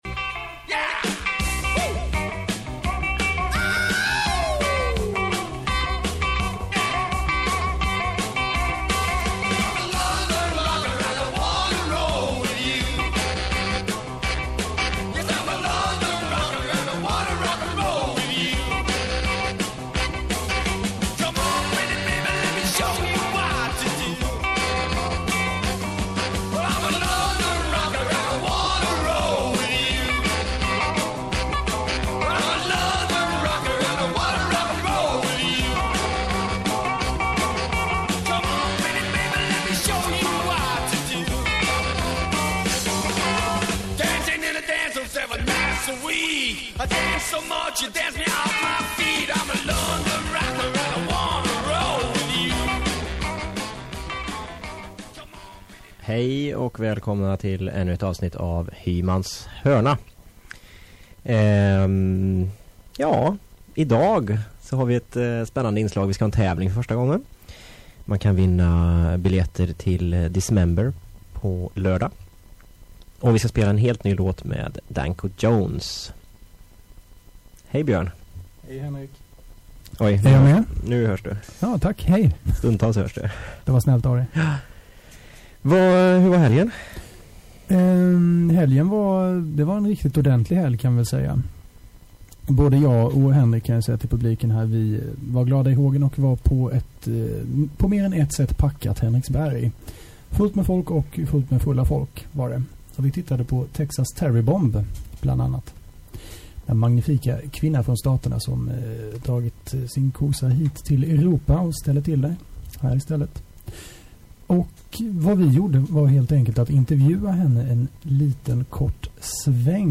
Tyv�rr �r ljudkvaliten inte helt hundra p� dagens program, musiken l�ter bra men v�ra r�ster �r lite distade, hoppas du kan st� ut med det...